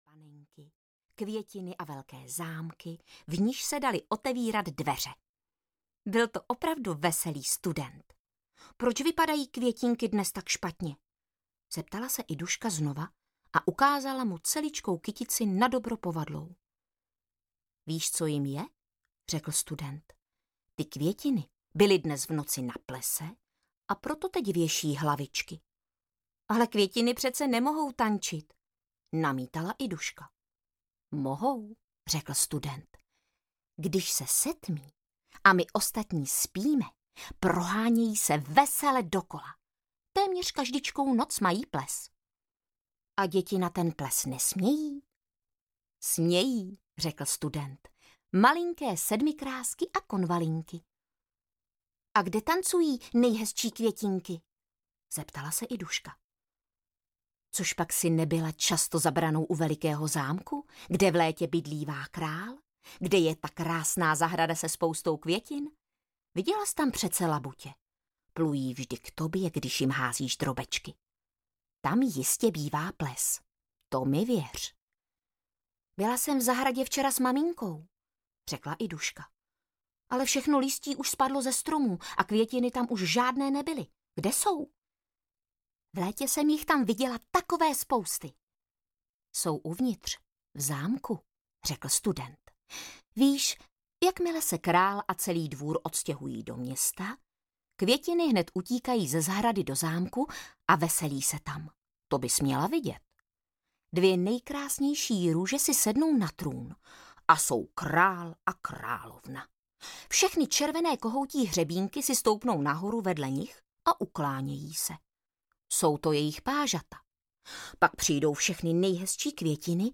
Iduščiny květiny audiokniha
Ukázka z knihy
idusciny-kvetiny-audiokniha